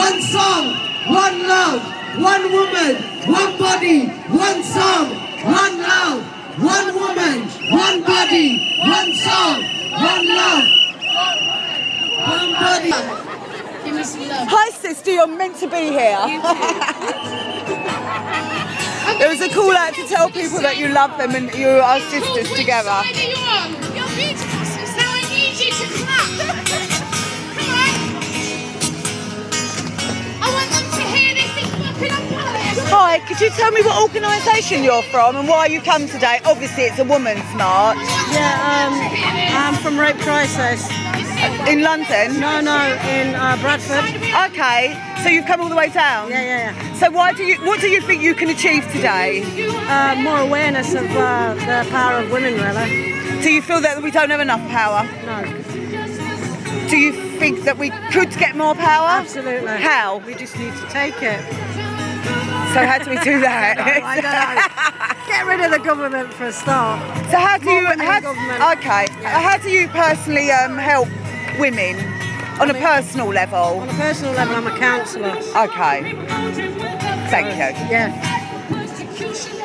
Speaking to a counsellor from Crisis in Bradford